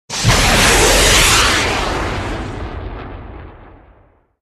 missile_launch.mp3